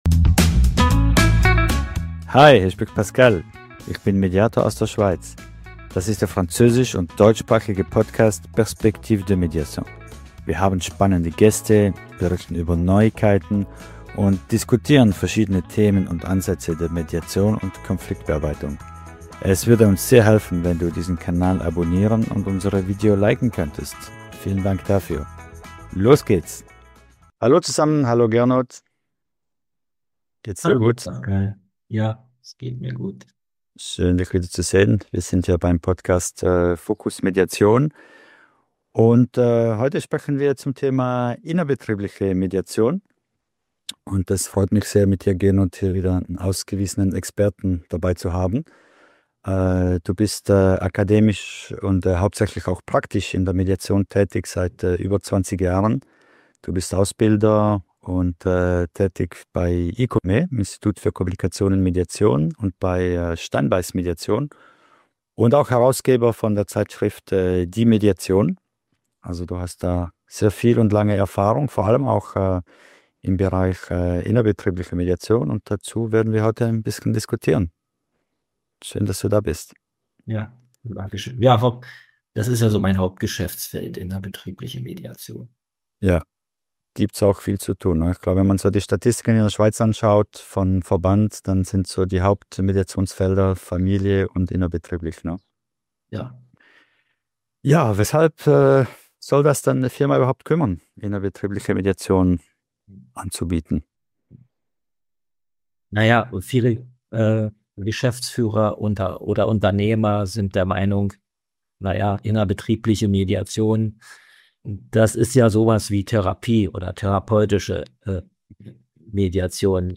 Willkommen beim Podcast Fokus Mediation!